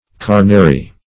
Search Result for " carnary" : The Collaborative International Dictionary of English v.0.48: Carnary \Car"na*ry\, n. [L. carnarium, fr. caro, carnis, flesh.]